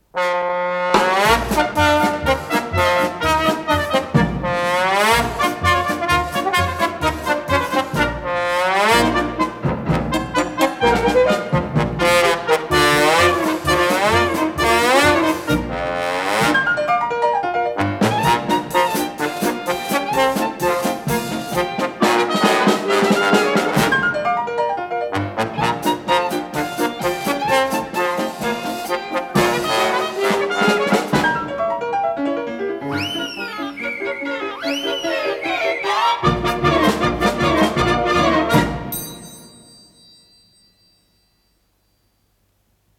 с профессиональной магнитной ленты
сюита, лёгкая музыка для малого оркестра